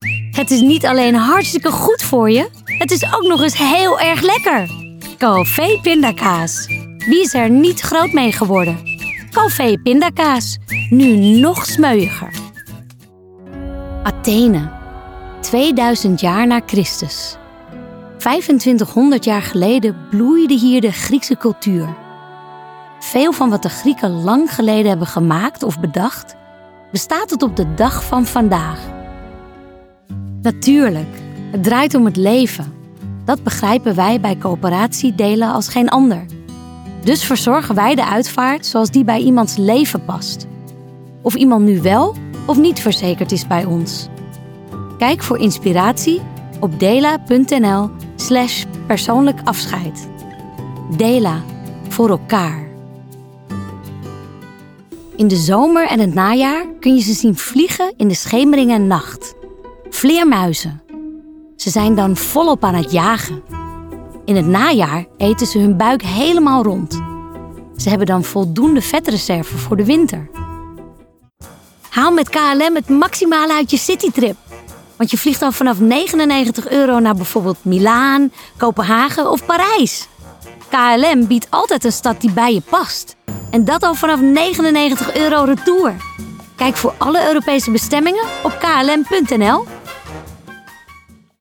stemdemo